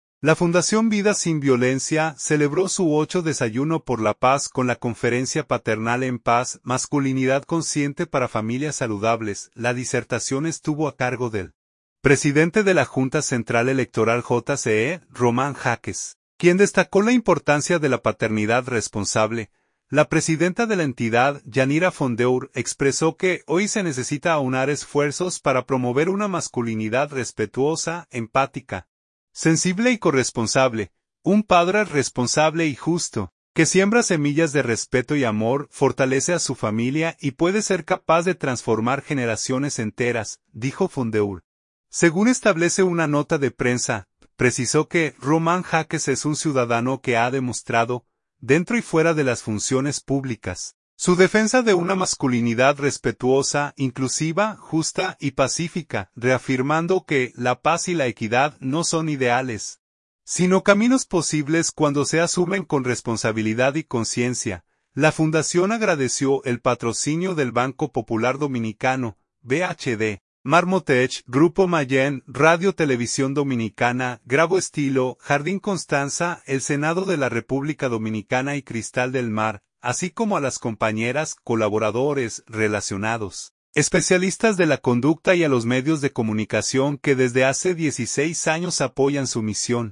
La Fundación Vida sin Violencia celebró su VIII Desayuno por la Paz con la conferencia “Paternal en paz: masculinidad consciente para familias saludables”.
La disertación estuvo a cargo del presidente de la Junta Central Electoral (JCE), Román Jáquez, quien destacó la importancia de la paternidad responsable.